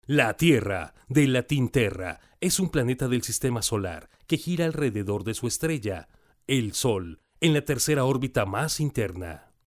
voz promocional, Cálida, cercana, emotiva, institucional
Locutor comercial , promocional, IVR , institucional, documental, E- learning, corporativa
spanisch Südamerika
Sprechprobe: eLearning (Muttersprache):